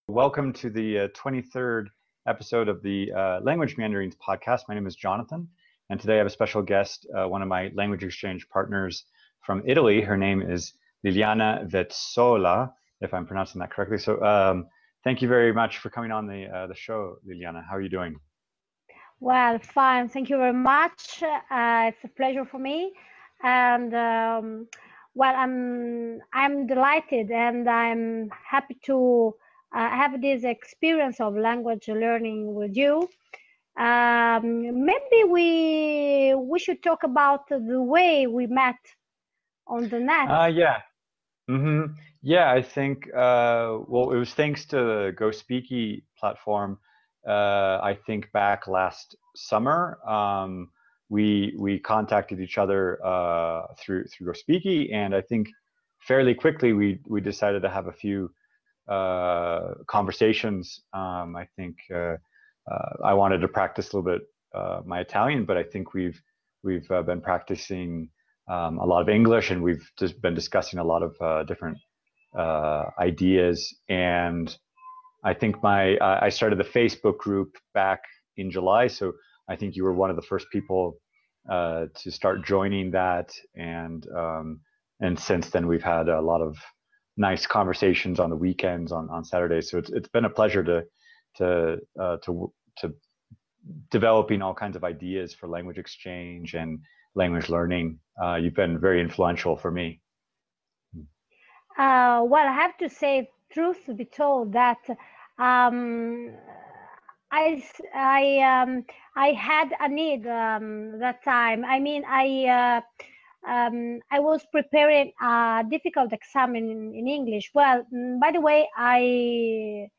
Episode 23 - Interview